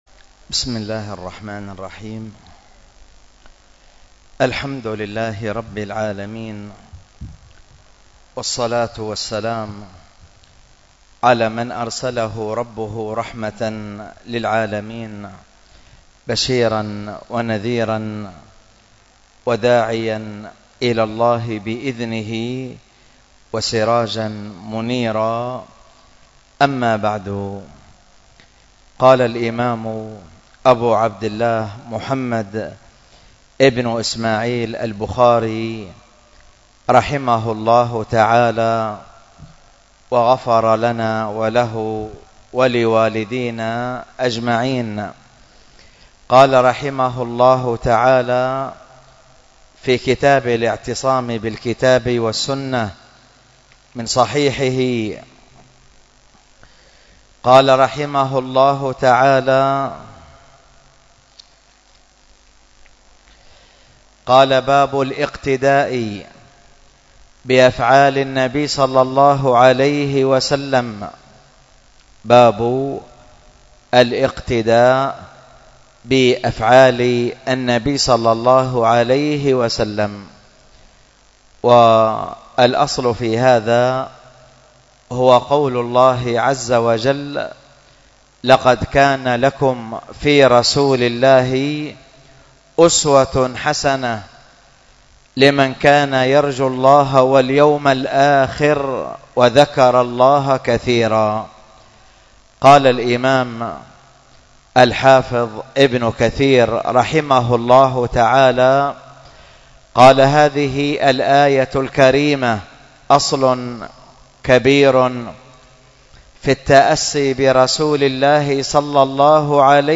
الدرس في كتاب الطهارة 26، ألقاها